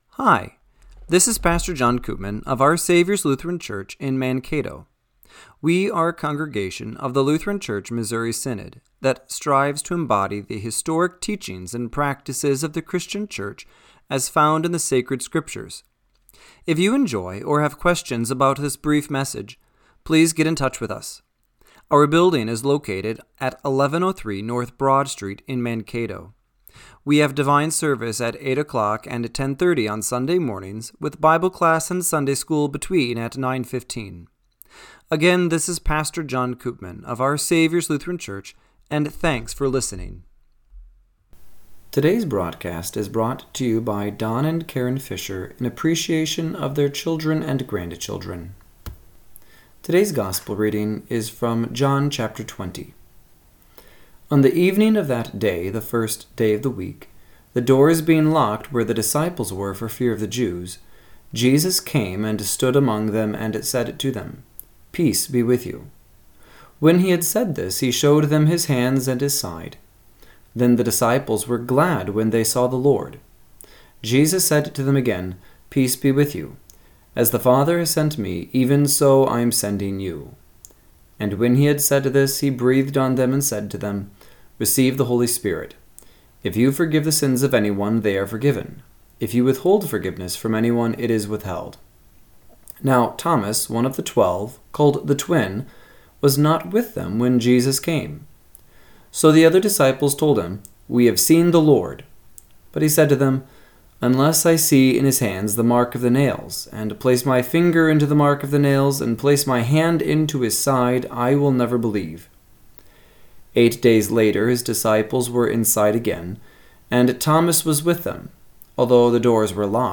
Radio-Matins-4-27-25.mp3